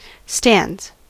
Ääntäminen
Synonyymit bleachers Ääntäminen US : IPA : [stændz] Haettu sana löytyi näillä lähdekielillä: englanti Käännöksiä ei löytynyt valitulle kohdekielelle.